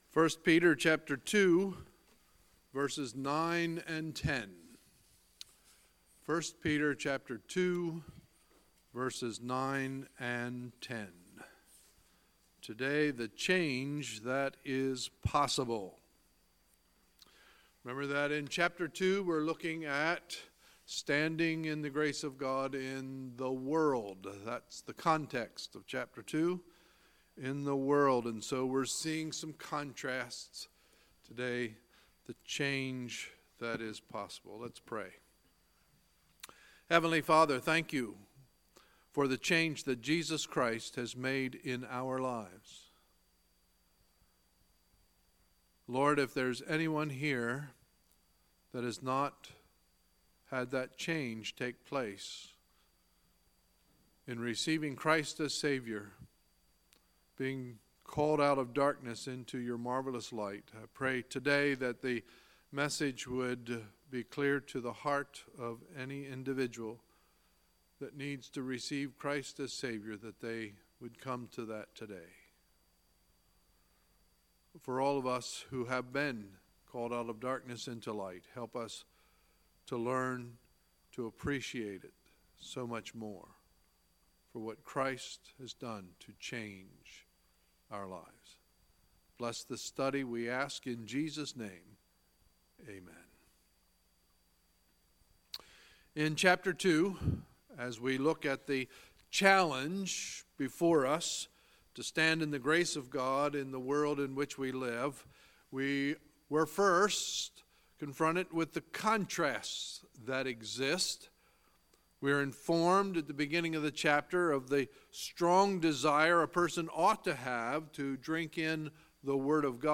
Sunday, April 22, 2018 – Sunday Morning Service